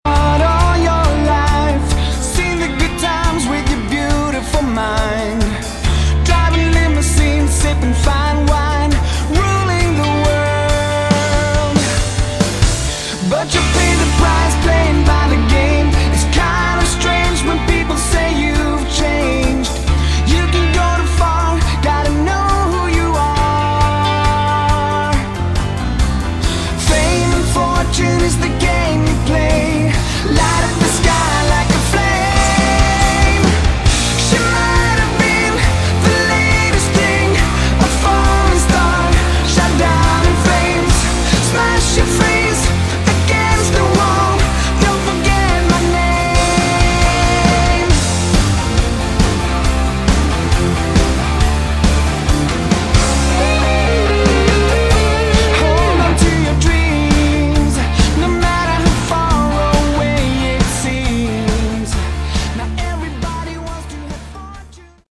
Category: Scandi AOR
keyboards
guitars
vocals
drums